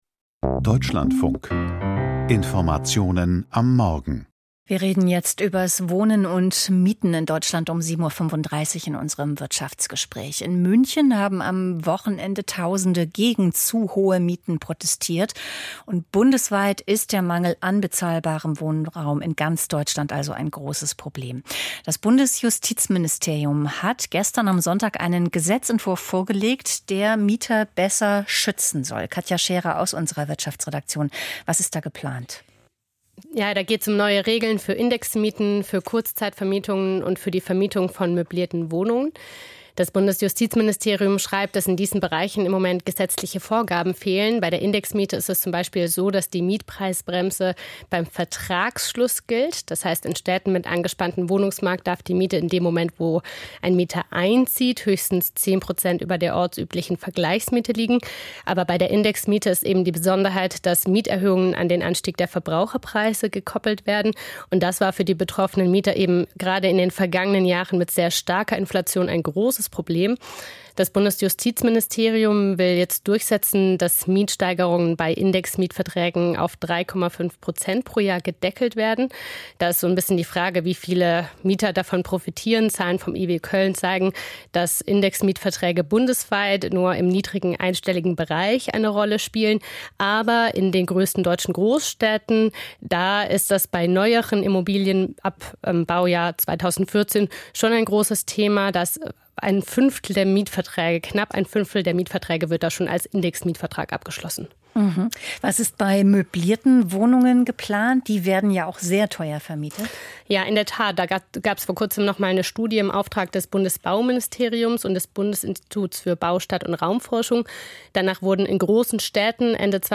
Wirtschaftsgespräch - Bundesjustizministerin Hubig gegen Wuchermieten